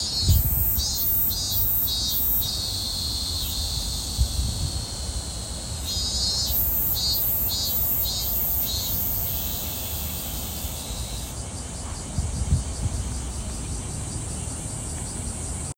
cicadas
cicadas.mp3